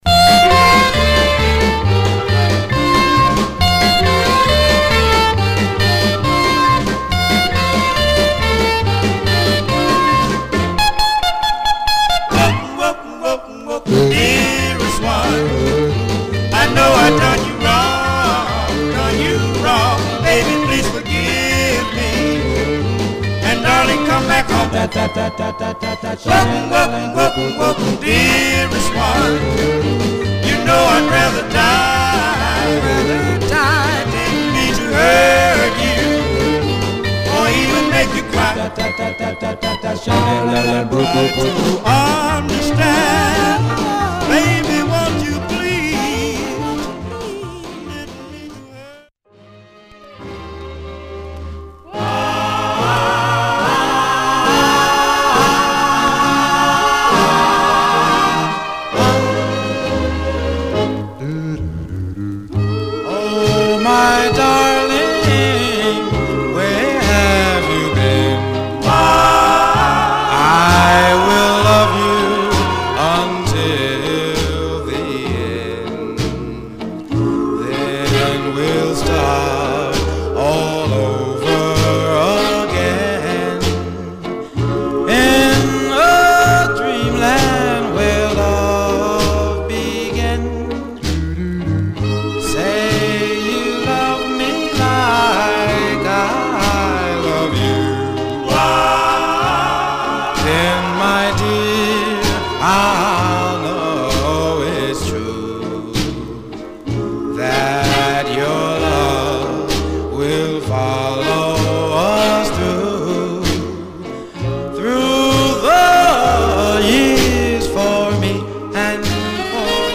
Stereo/mono Mono
Male Black Group Condition